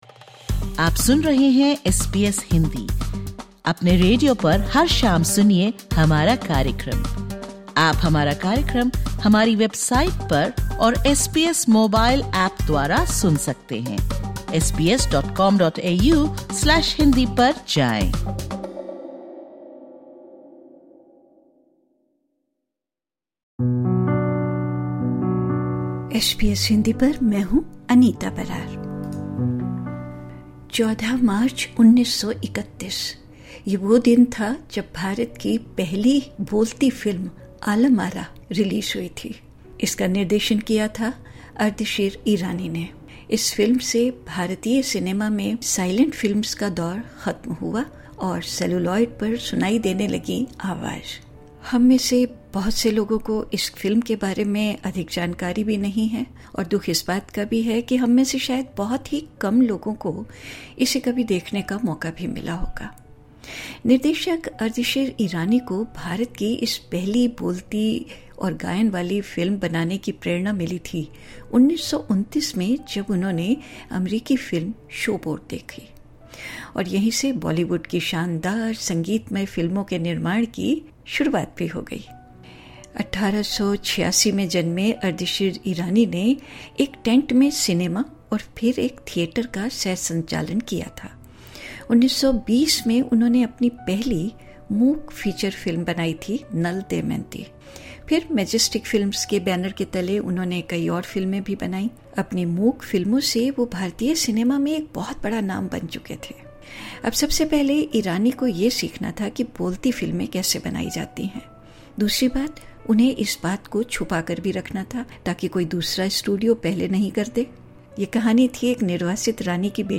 भारत की पहली बोलती फिल्म आलम आरा 14 मार्च 1931 के दिन रिलीज हुई लेकिन निर्देशक अर्देशिर ईरानी की इस अग्रणी फिल्म का आज कोई प्रिंट उपलब्ध नहीं है। इस पॉडकास्ट में भारतीय राष्ट्रीय फिल्म अभिलेखागार (NFAI) के निदेशक स्वर्गीय पी के नायर की एस बी एस हिन्दी के साथ एक खास बातचीत के एक अंश से जाने कि यह फिल्म कहाँ चली गयी।